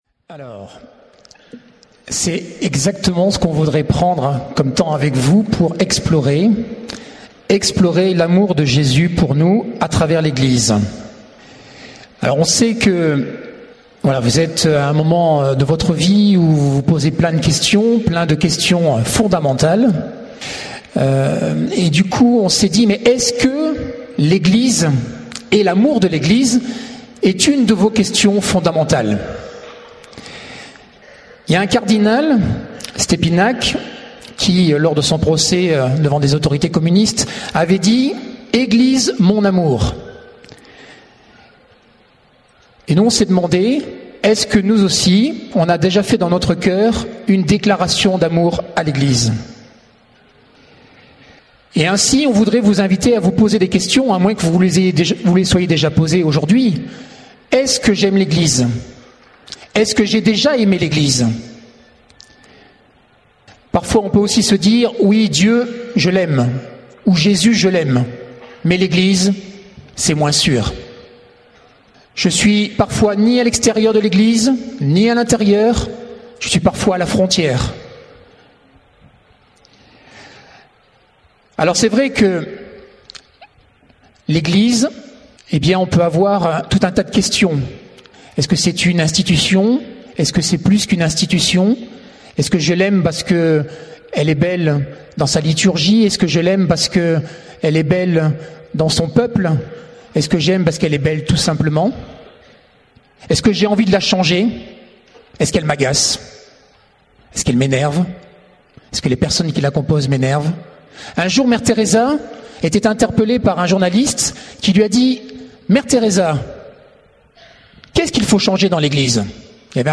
Forum des jeunes (du 16 au 20/08/2012) Grand Enseignement Enregistr� le 19 ao�t 2012.